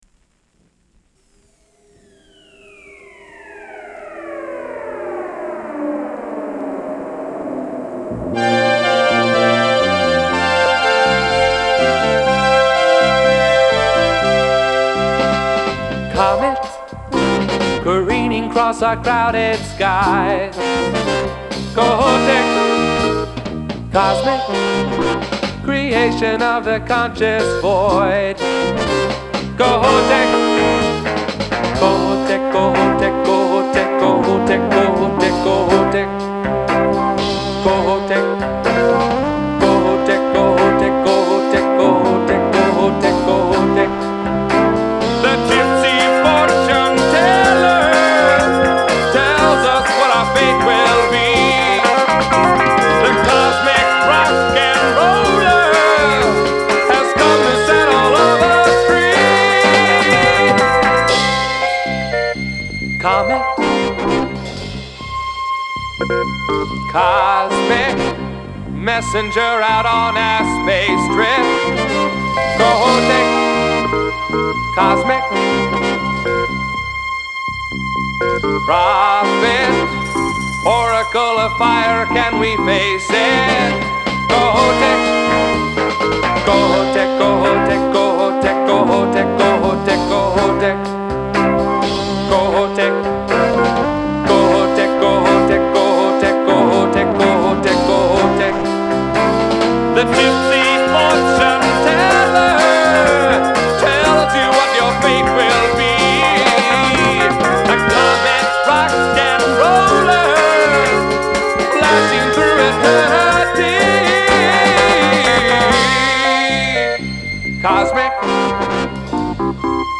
ARP Synthesizer